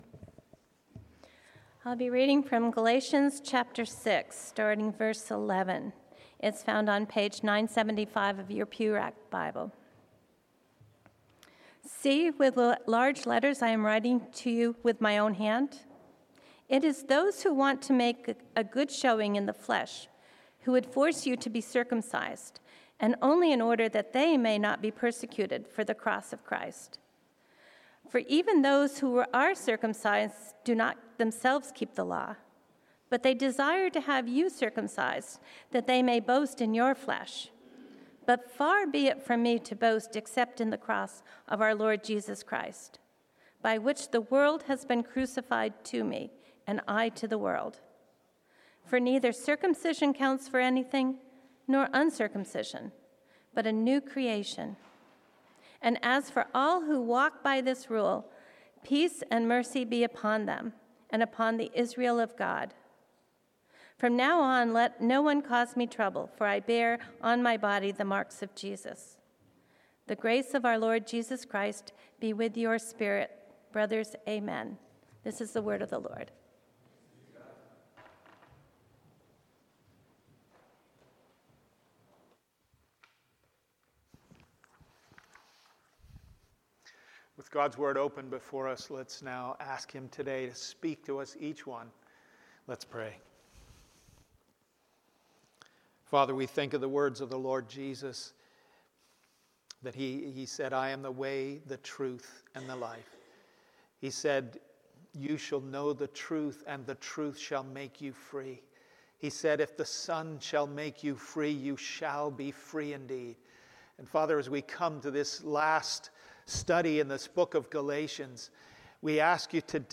Passage: Galatians 6:11-18 Sermon